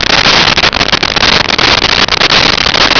Sfx Amb Wfalls Far Loop
sfx_amb_wfalls_far_loop.wav